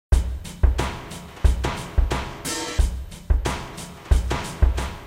Rock-12.wma